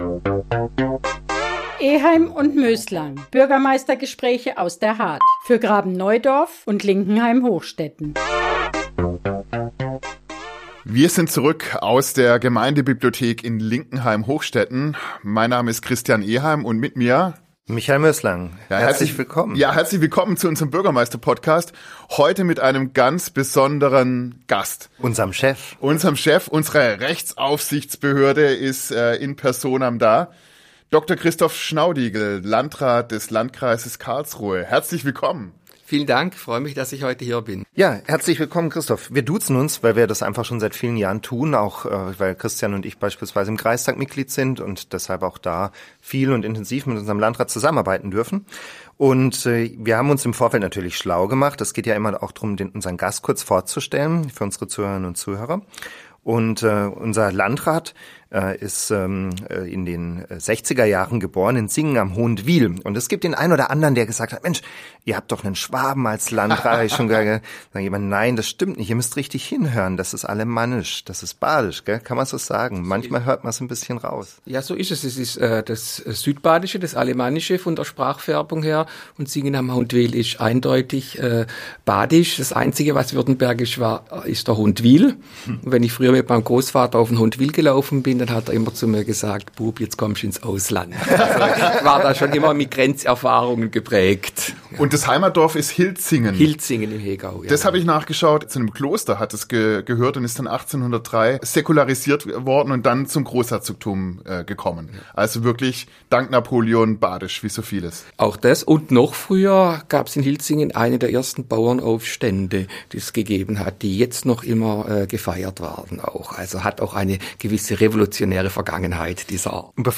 Eheim & Möslang – Der Bürgermeister-Podcast Folge 9 ~ Eheim & Möslang - Bürgermeistergespräche aus der Hardt für Graben-Neudorf und Linkenheim-Hochstetten Podcast